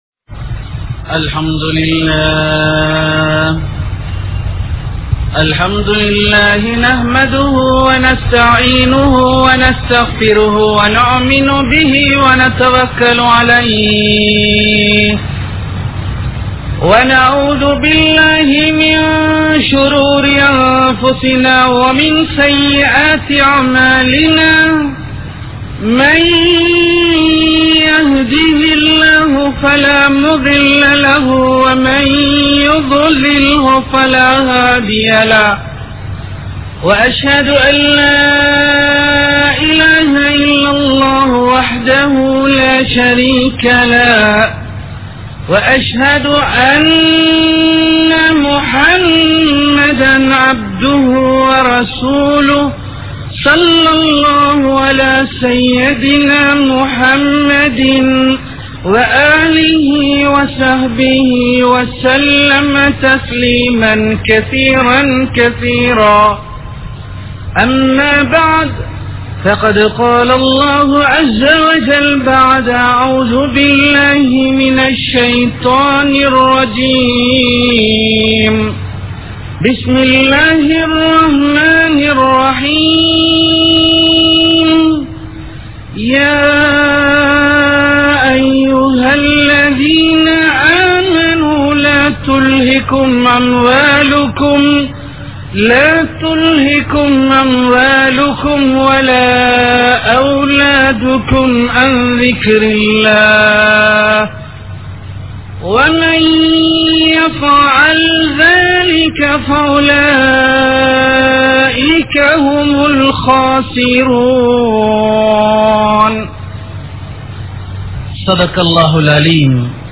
Kulanthaip Paakkiyam (குழந்தைப் பாக்கியம்) | Audio Bayans | All Ceylon Muslim Youth Community | Addalaichenai
Colombo 03, Kollupitty Jumua Masjith